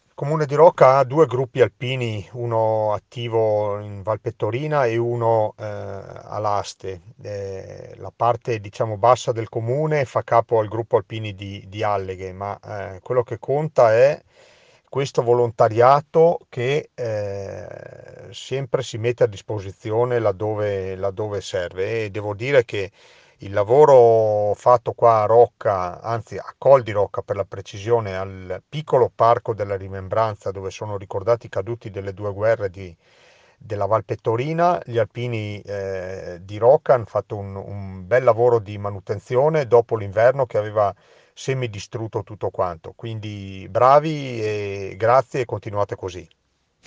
IL SINDACO DI ROCCA PIETORE ANDREA DE BERNARDIN